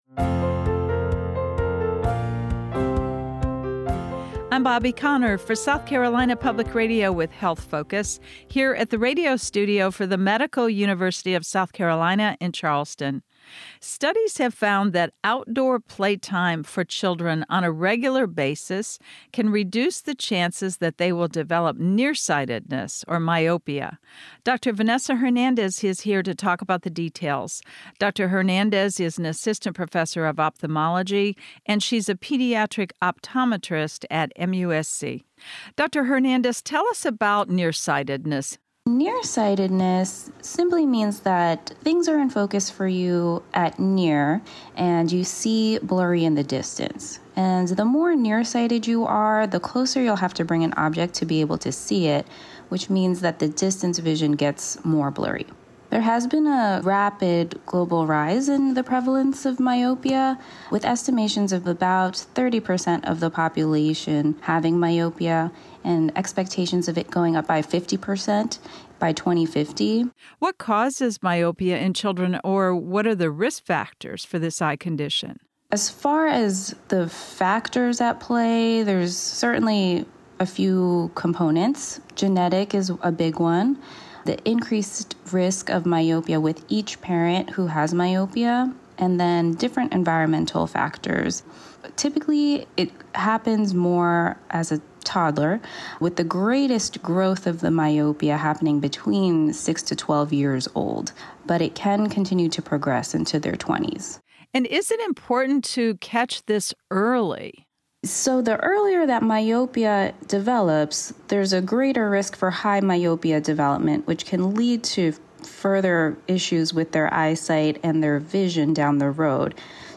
Doctors, medical professionals and researchers from the Medical University of South Carolina will be featured weekly in a series of radio interviews on South Carolina Public Radio called Health Focus.